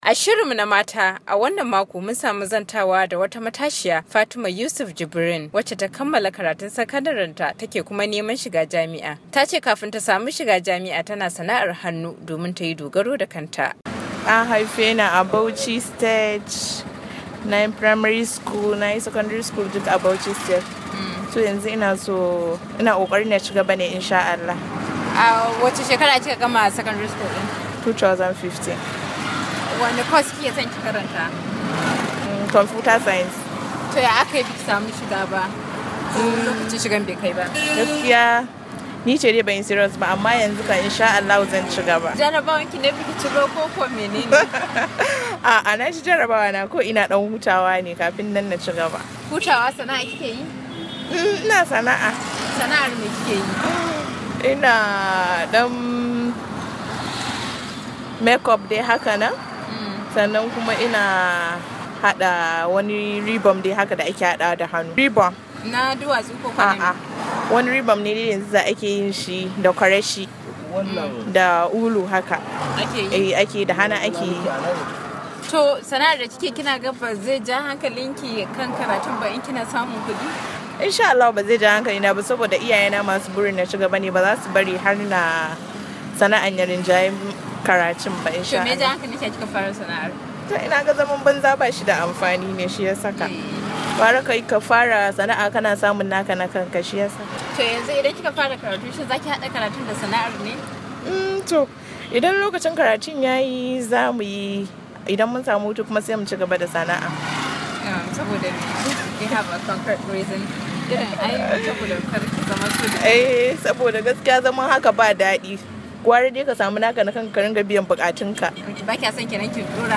Saurari hirar a nan.